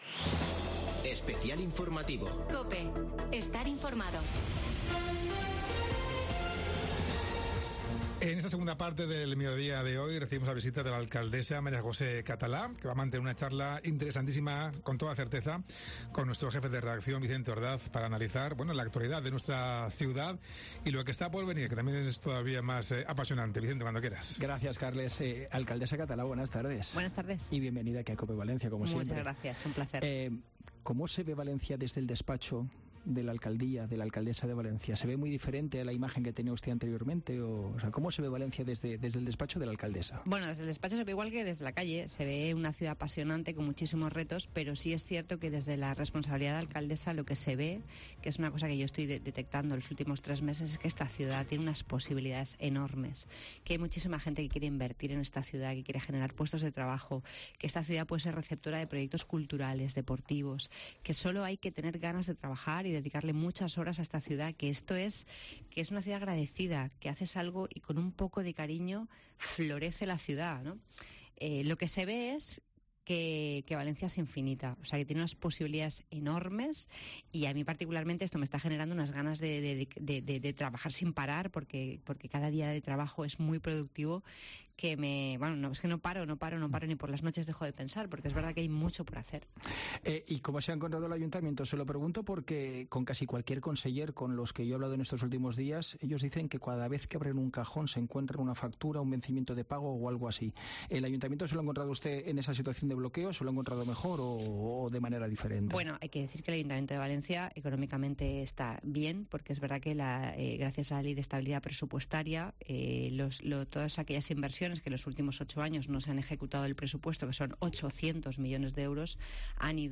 La alcaldesa de Valencia visita COPE